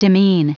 Prononciation du mot demean en anglais (fichier audio)
Prononciation du mot : demean